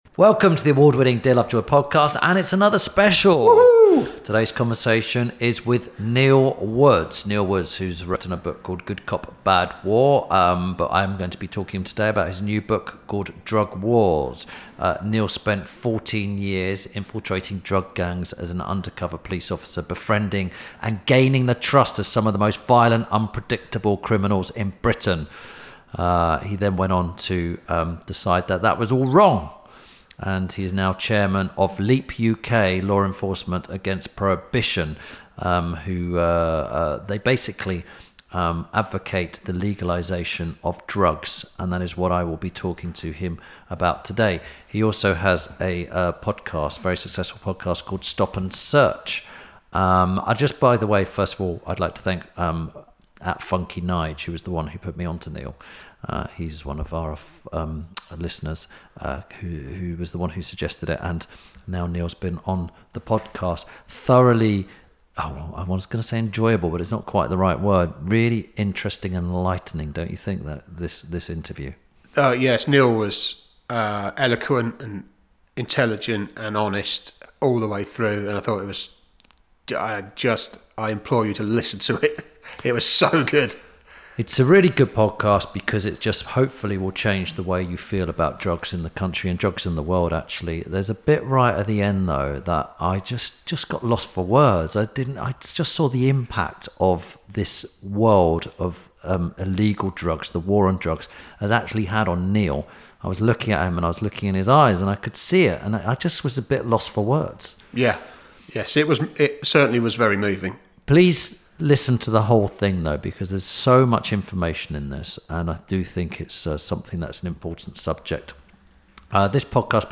– INTERVIEW SPECIAL